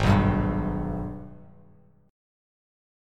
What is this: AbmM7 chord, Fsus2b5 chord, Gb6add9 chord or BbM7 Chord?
AbmM7 chord